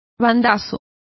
Complete with pronunciation of the translation of lurches.